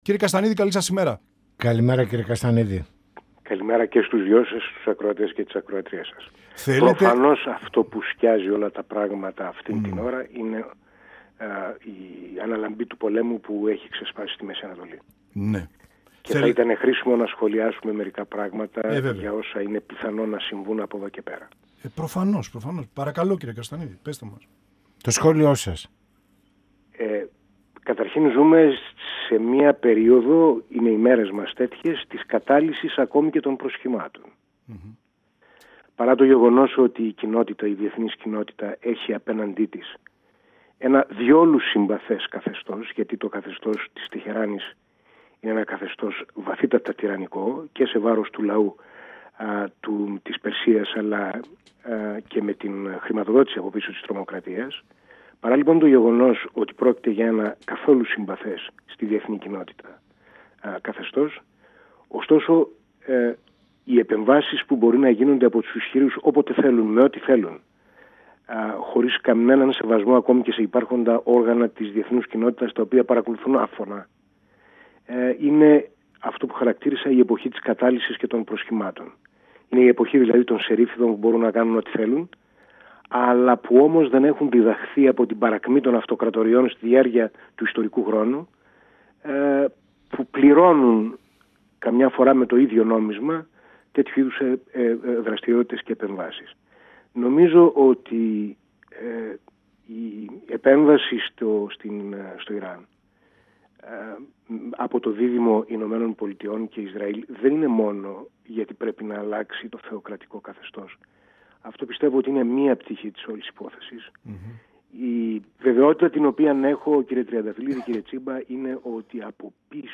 Στις πρώτες μέρες του πολέμου στη Μέση Ανατολή, στους στόχους ΗΠΑ και Ισραήλ σε σχέση με την αλλαγή του θεοκρατικού καθεστώτος στο Ιράν, καθώς και την πολλαπλή αντίδραση του Ιράν με μη αναμενόμενες πυραυλικές επιθέσεις του στις γειτονικές αραβικές χώρες του Κόλπου αναφέρθηκε ο π. Υπουργός του ΠΑΣΟΚ Χάρης Καστανίδης, μιλώντας στην εκπομπή «Πανόραμα Επικαιρότητας» του 102FM της ΕΡΤ3.
Συνεντεύξεις